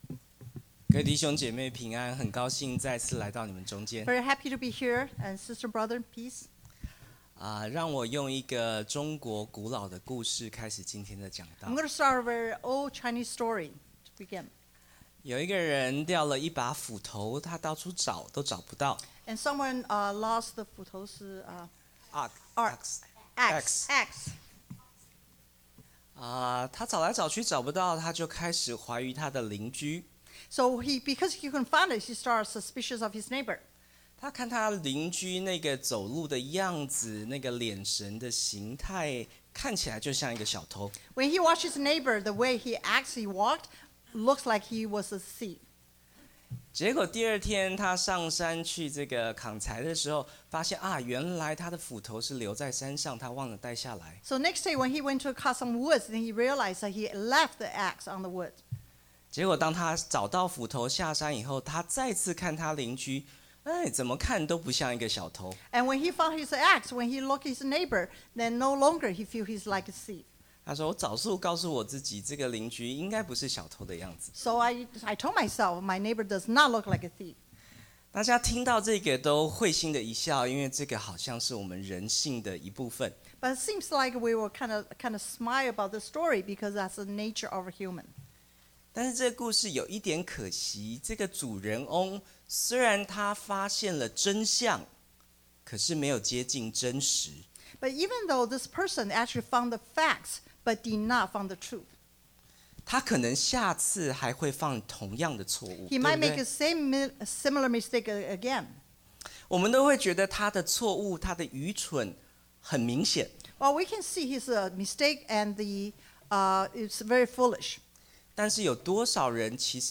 Matt.7:1-6 Service Type: Sunday AM Bible Text